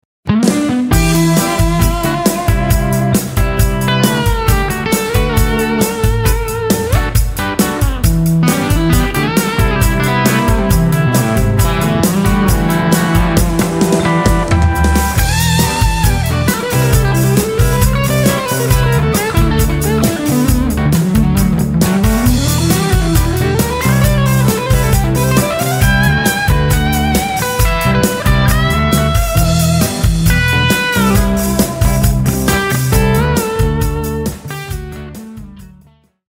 • Foot-switchable overdrive section adds versatility and includes dedicated controls for Volume, Tone and Drive. Together with the compressor it produces a sound that's saturated, smooth and slippery
Compressor On, Drive Setting Low
PlatformStereo-Compressor-Limiter-Compressor-On-Drive-Setting-Low.mp3